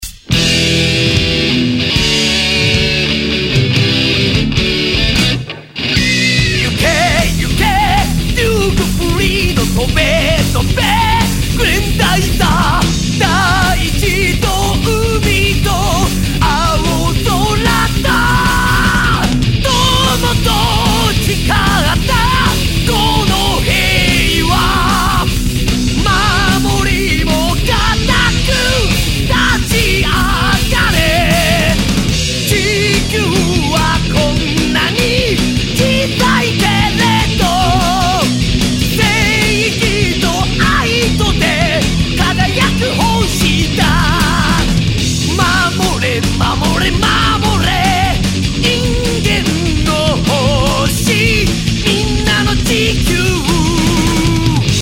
une version hard